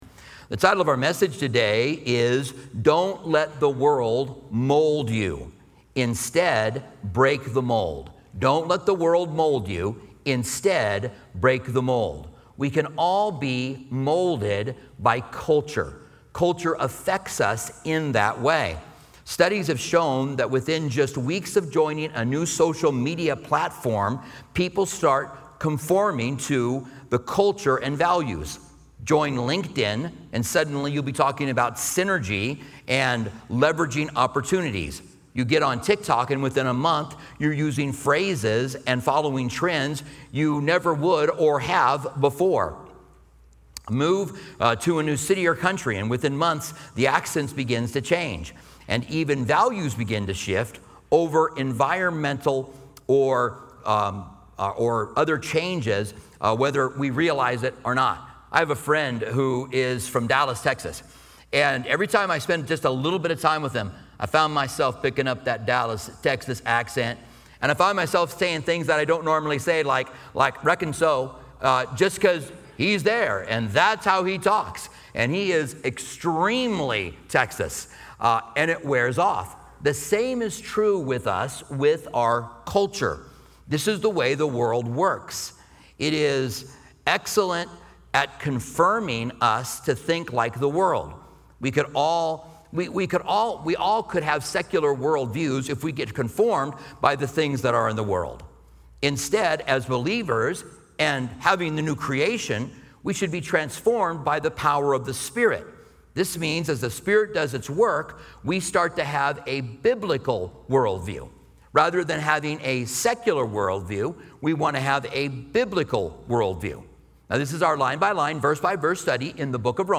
This sermon encourages Christians to resist societal pressures and embrace transformation through a renewed mindset, inspired by the Holy Spirit. Key themes include the need for daily surrender as an act of worship, breaking free from secular worldviews, and discovering God's perfect will for our lives.